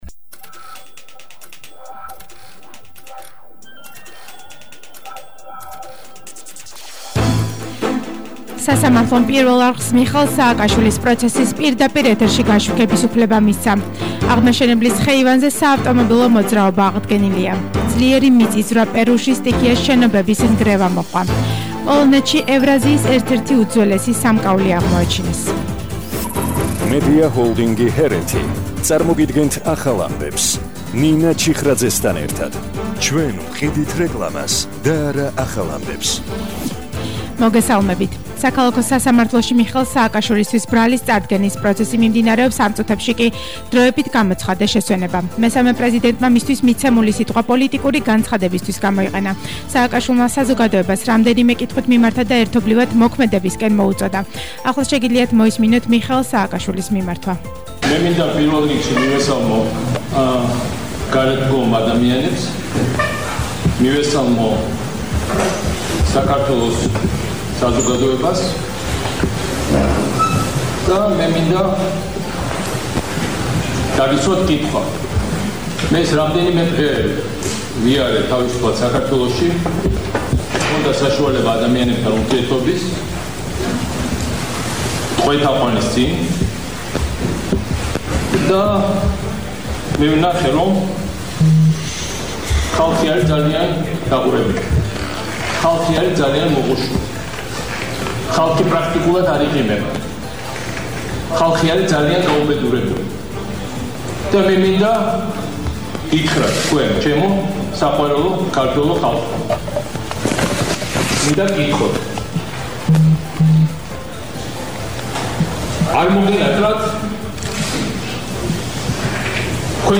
ახალი ამბები 15:00 საათზე –29/11/21